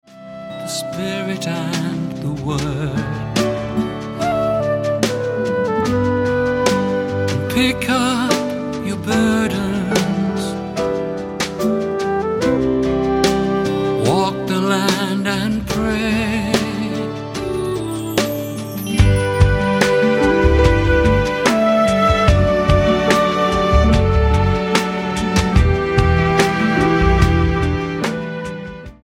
STYLE: MOR / Soft Pop